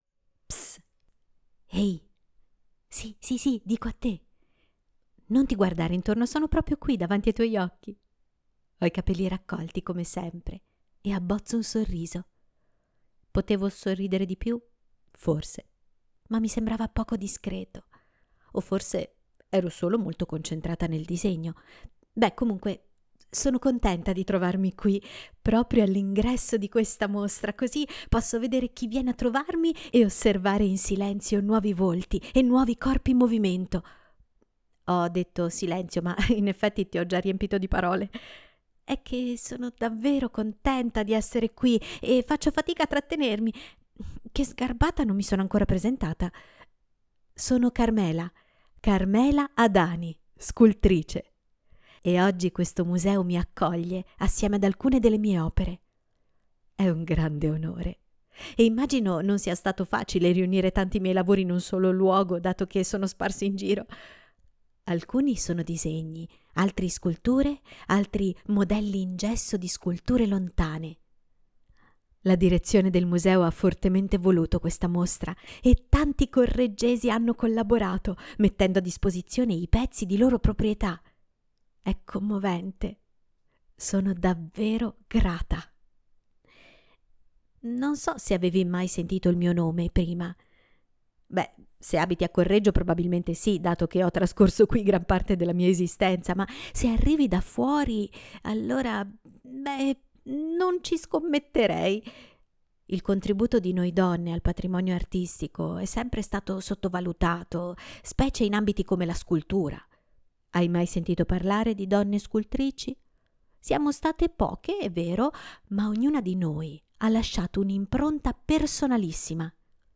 Il primo è una particolare audioguida che vede come protagonista la stessa Carmela, la quale utilizzerà le opere presenti nella mostra come pretesto per raccontare la sua vita e la sua arte.